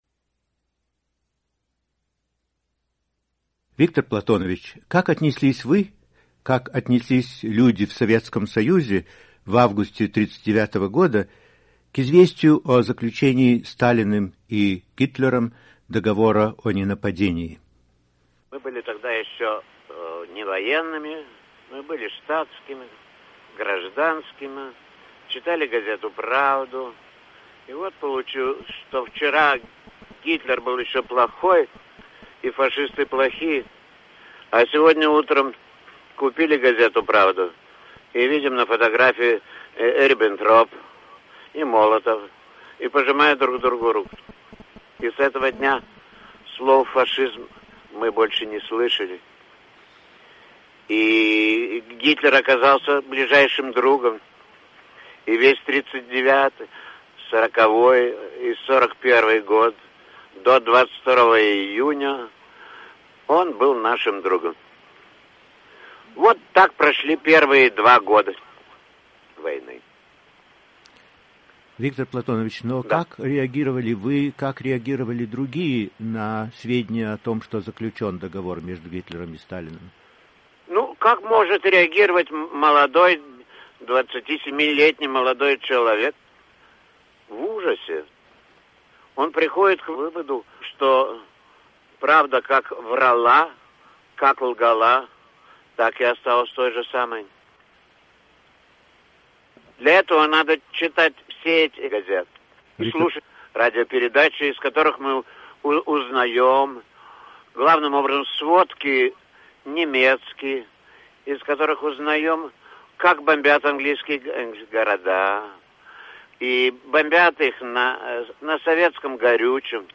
Писатель-фронтовик Виктор Некрасов, автор культового романа «В окопах Сталинграда», добившийся установки памятника евреям, убитым в Бабьем Яру, вспоминает о нападении нацистской Германии на Советский Союз. Интервью Русской службе «Голоса Америки», предположительно 1981 год